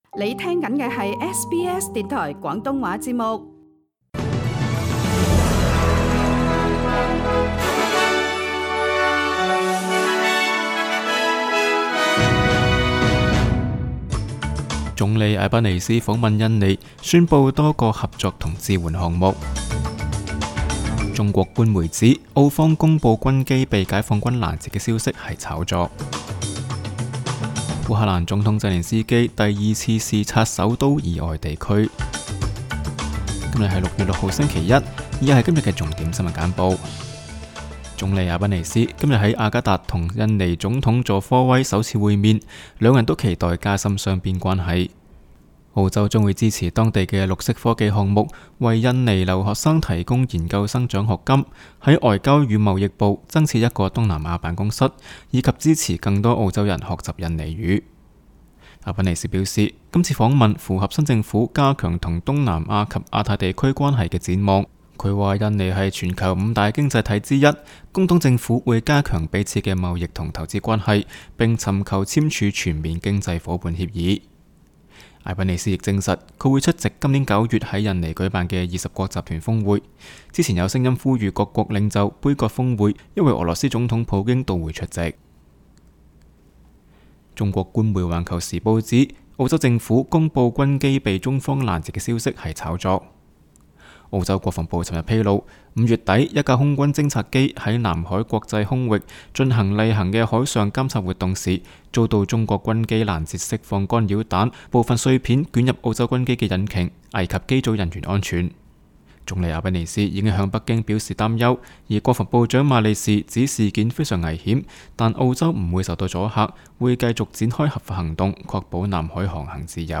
SBS 新闻简报（6月6日）
SBS 廣東話節目新聞簡報 Source: SBS Cantonese